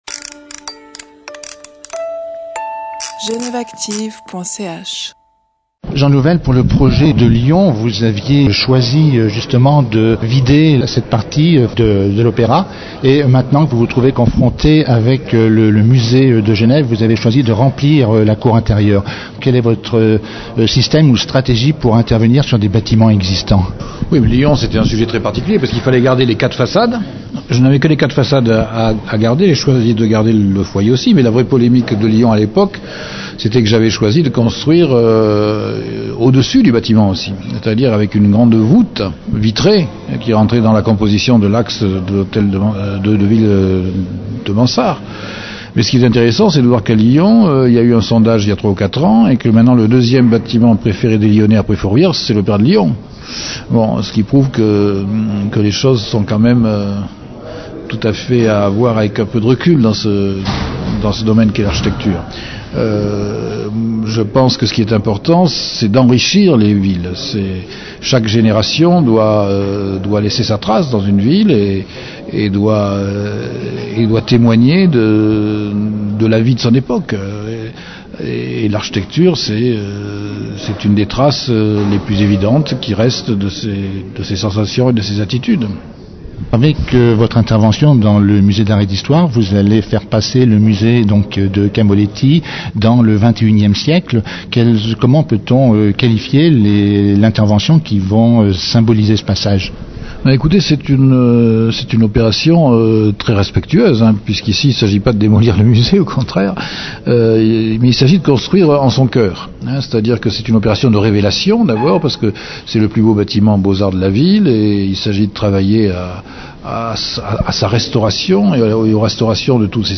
Jean Nouvel nous parle de son projet